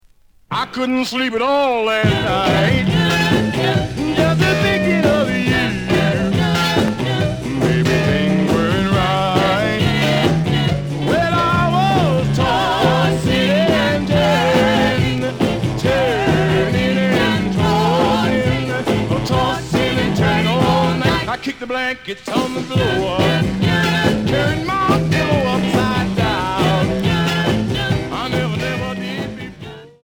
試聴は実際のレコードから録音しています。
●Genre: Rhythm And Blues / Rock 'n' Roll
●Record Grading: VG~VG+